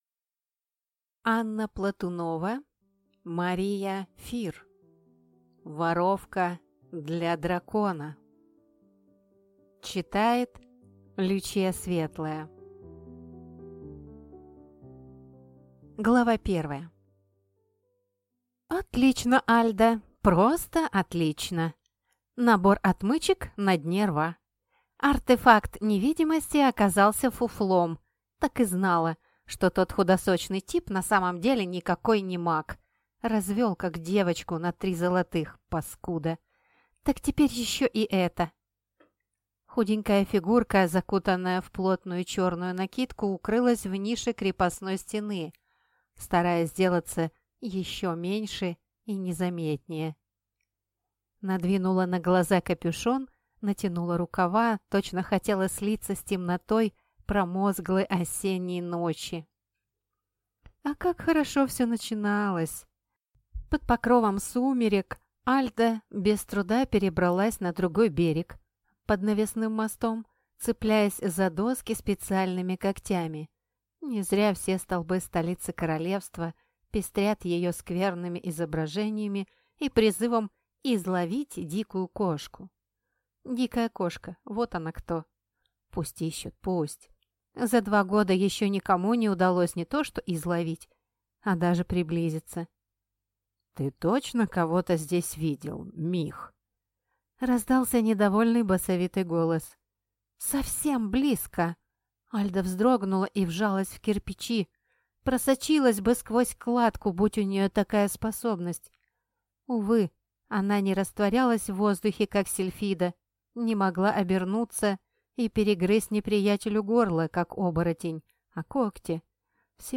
Аудиокнига Воровка для дракона | Библиотека аудиокниг